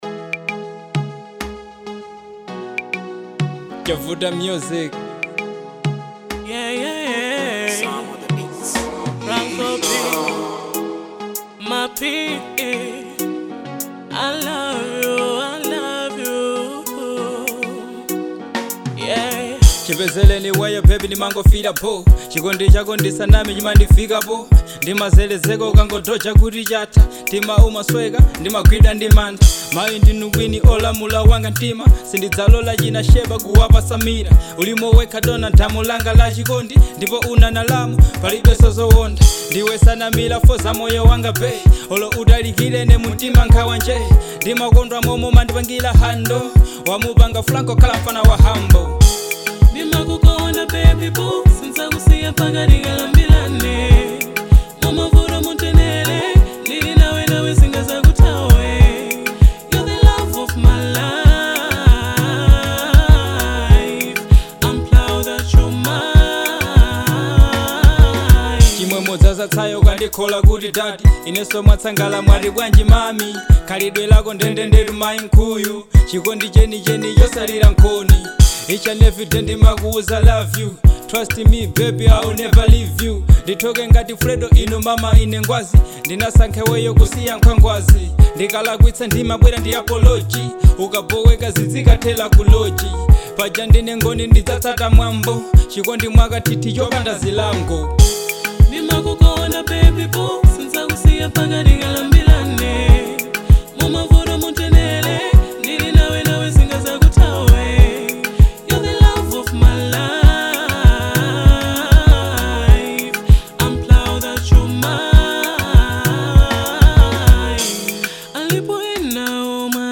Afro song